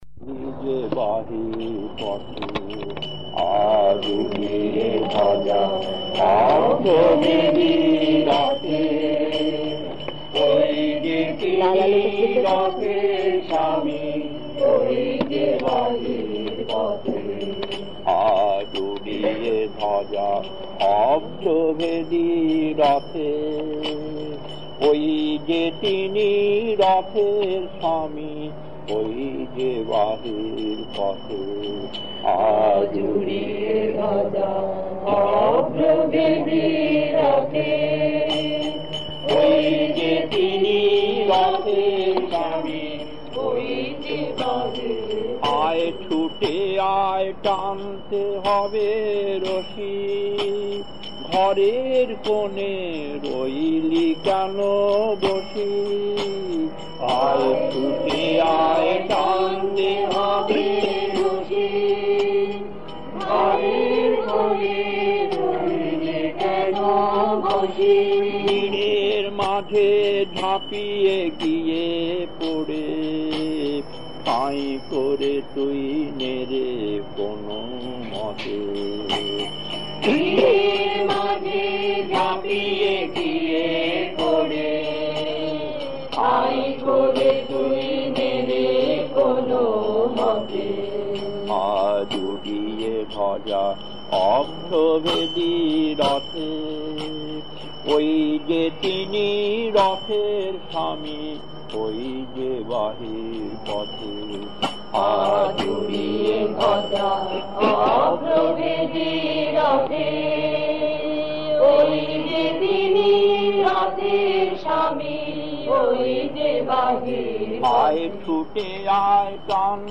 Kirtan C12-1 Puri, 1982, 27 minutes 1.